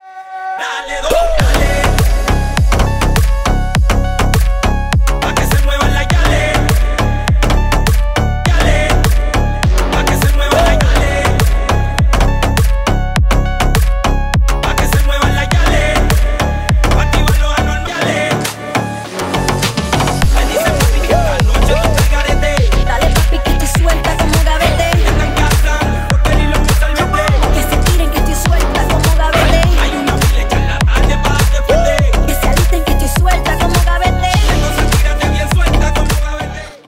мужской голос
зажигательные
женский голос
качающие
Стиль: deep house